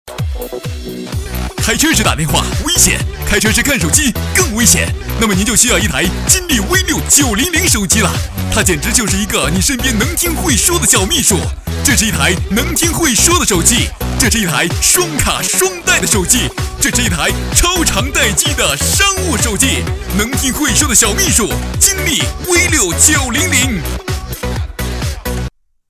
男国184年轻活力时尚配音-新声库配音网
男国184_广告_电购_手机电购.mp3